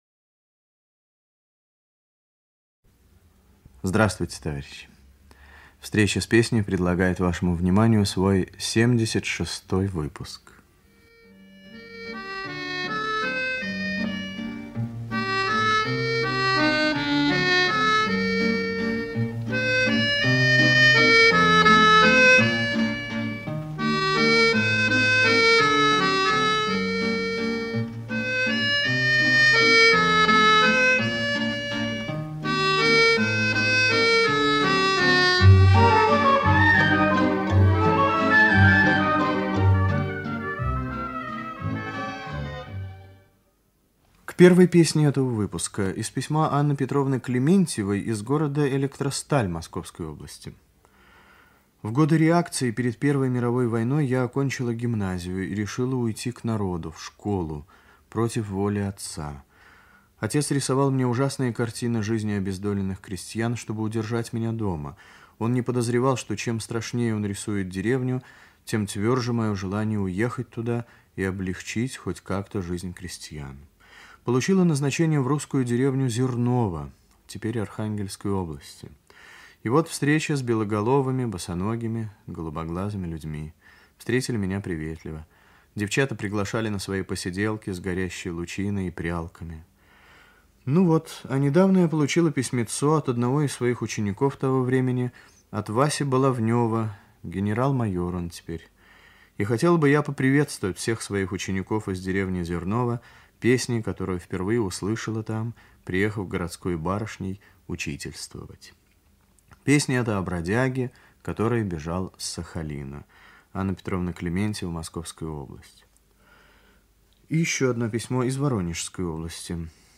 Русская народная песня